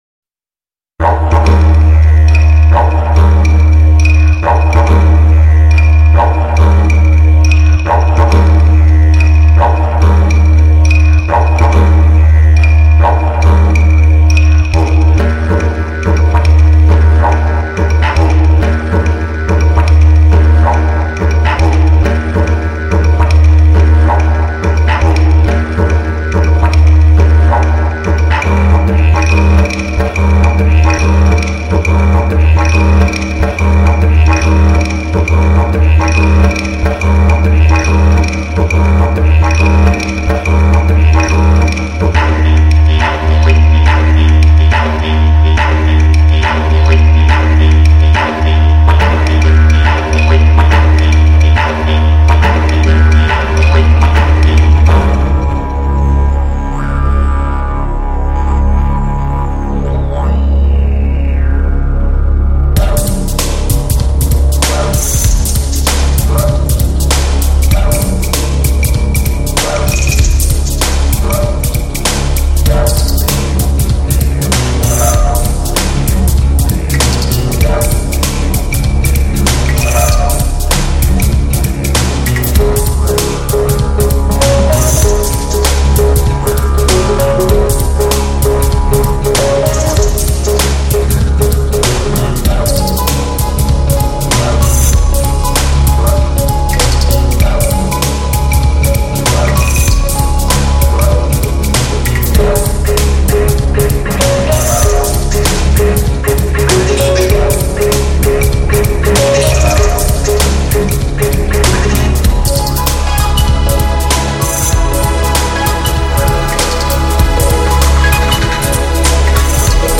专辑格式：DTS-CD-5.1声道
自然、清新、愉快、有氧。